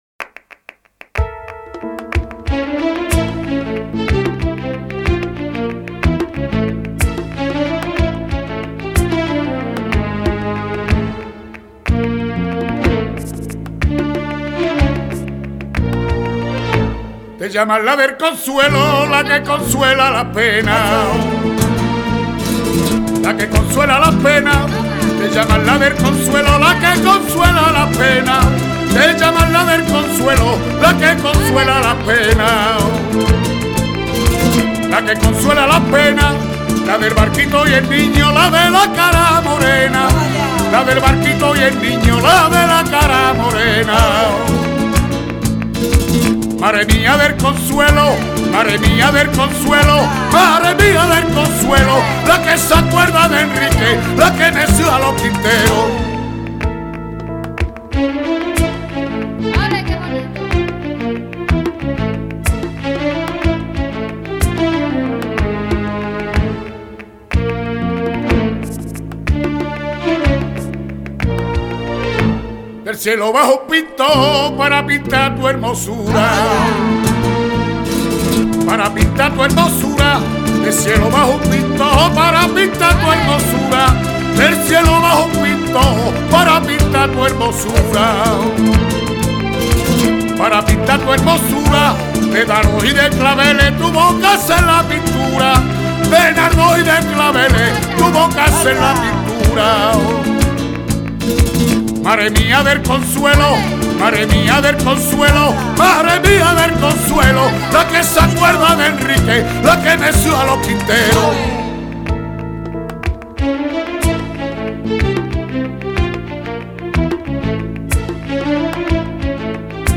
Sevillanas